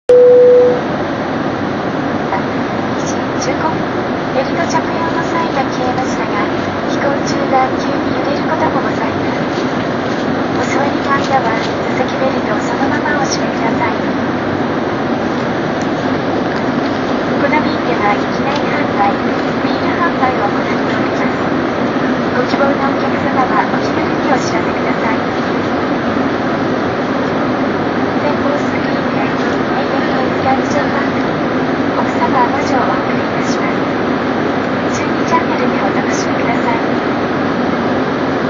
seatbeltsignoff.wma